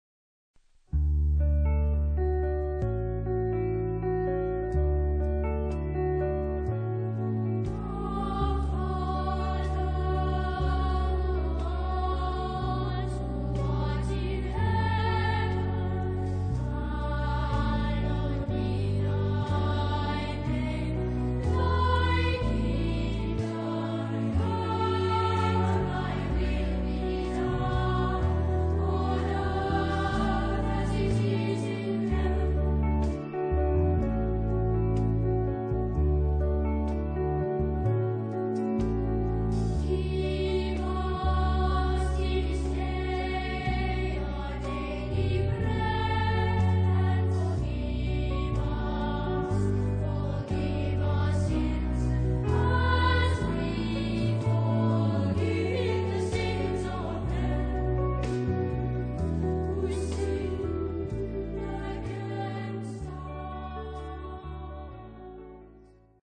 Genre-Style-Forme : Extrait de messe ; Sacré
Type de choeur : SATB  (4 voix mixtes )
Instruments : Enregistrement électronique (1)